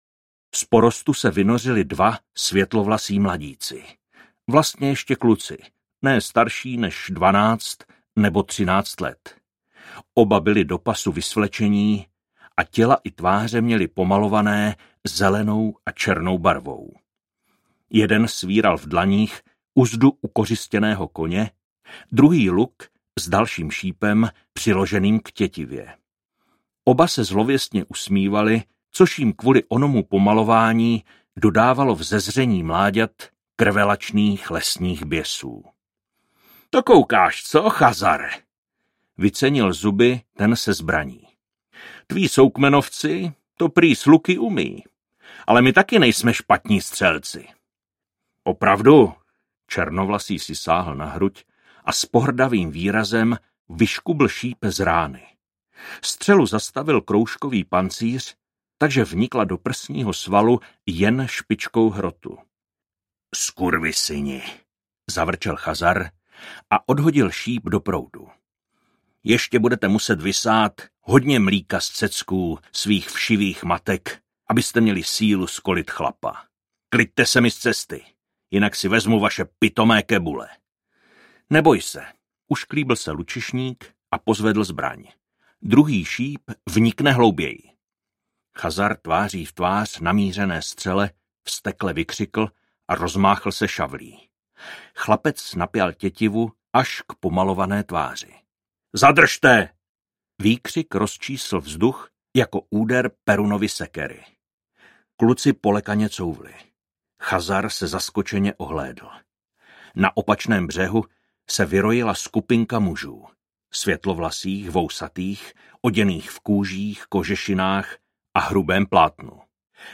Strážce hvozdu audiokniha
Ukázka z knihy
Vyrobilo studio Soundguru.